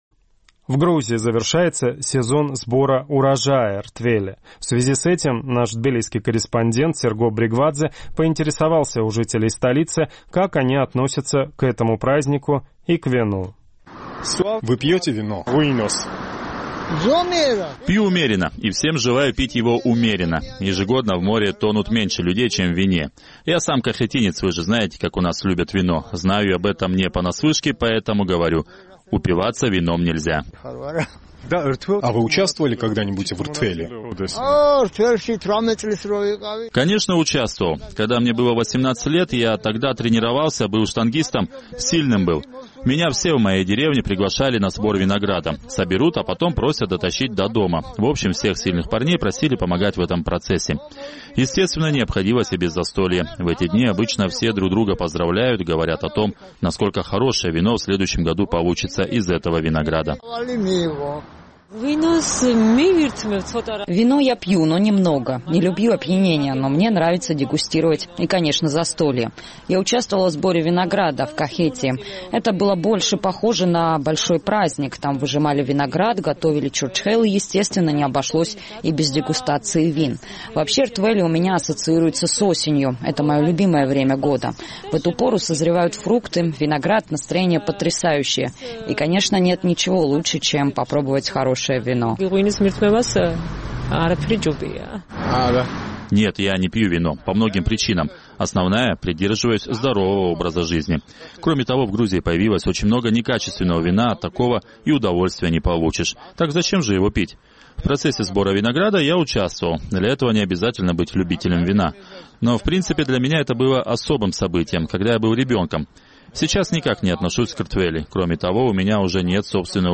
В Грузии завершается сезон сбора урожая – ртвели. В связи с этим мы поинтересовались у жителей Тбилиси, как они относятся и к этому празднику, и к вину.